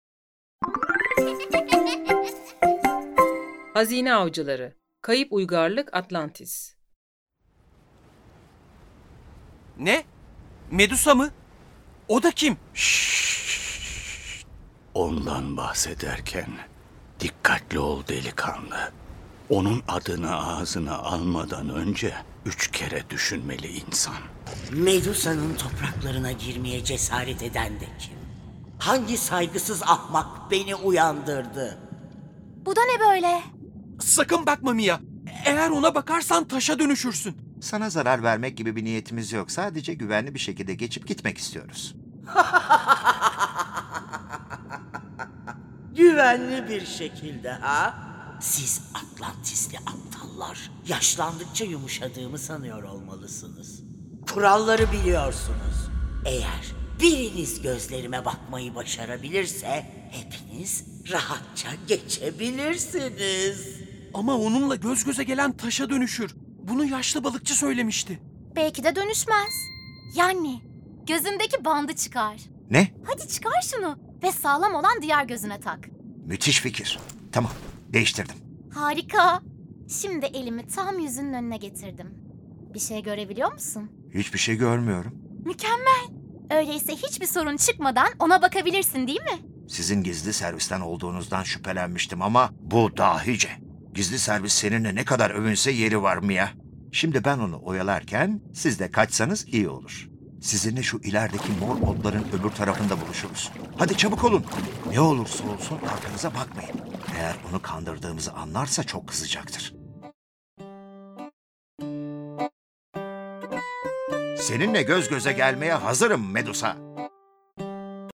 Hazine Avcıları-Kayıp Şehir Atlantis Tiyatrosu